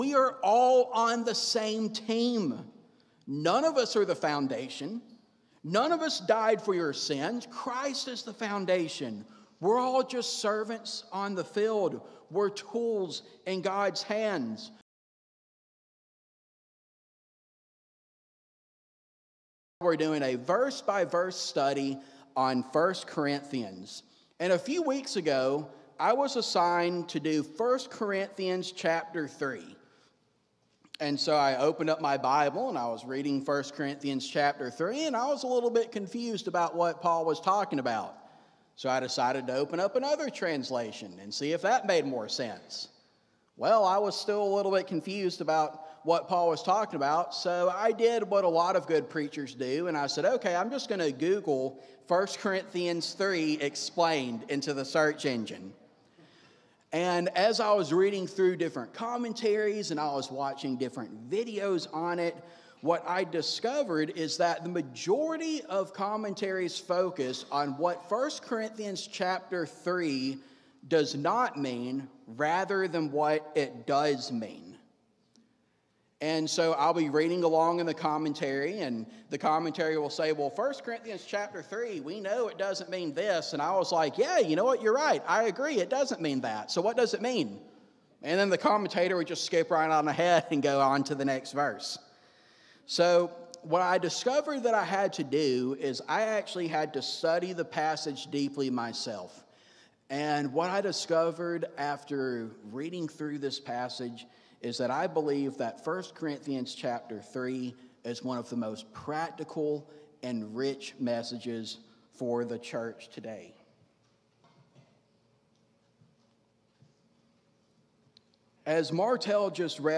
Recording from North Tampa Church of Christ in Lutz, Florida.